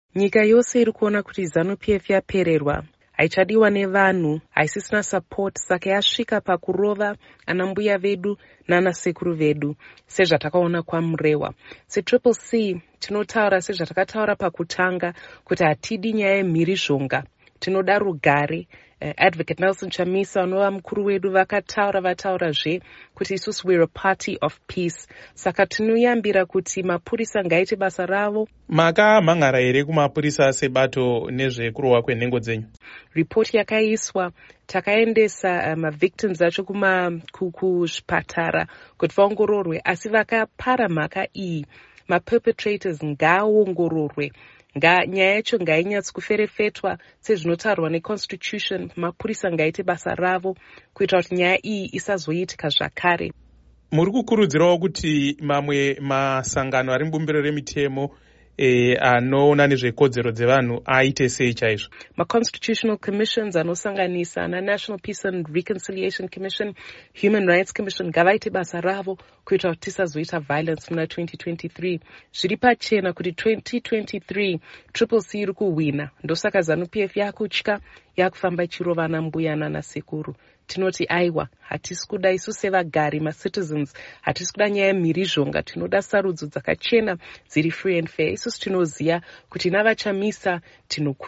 Hurukuro naMuzvare Fadzayi Mahere